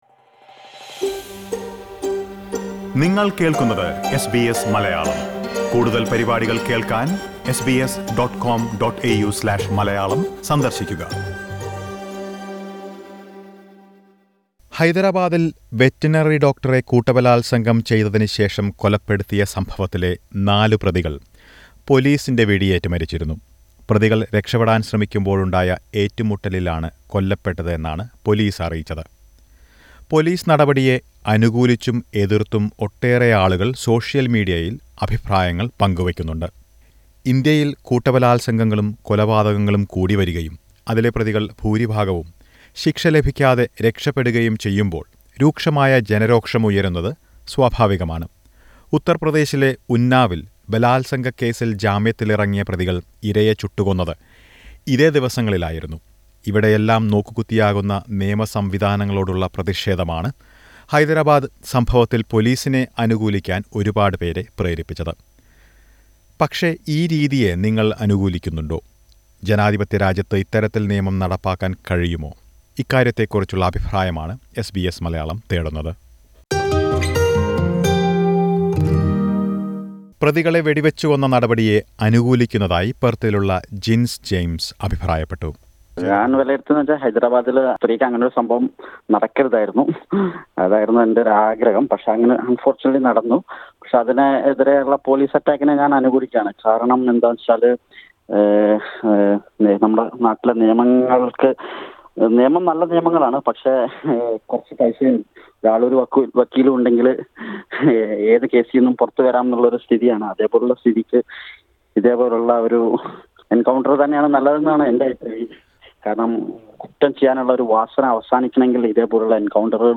ഹൈദരാബാദില്‍ ഒരു യുവ ഡോക്ടറെ ക്രൂരമായി കൂട്ടബലാത്സംഗം ചെയ്ത്, ചുട്ടുകൊന്ന കേസിലെ നാലു പ്രതികൾ പൊലീസ് വെടിവെയ്‌പ്പിൽ കൊല്ലപ്പെട്ട പശ്ചാത്തലത്തില്‍, എസ് ബി എസ് മലയാളം ഇതേക്കുറിച്ചുള്ള ഓസ്‌ട്രേലിയൻ മലയാളികളുടെ അഭിപ്രായങ്ങൾ തേടി.
ഈ വിഷയത്തിൽ ഓസ്‌ട്രേലിയൻ മലയാളികളും പ്രതികരിച്ചു. അനുകൂലിച്ചും എതിർത്തുമുള്ള അഭിപ്രായങ്ങൾ ഇവിടെ കേൾക്കാം.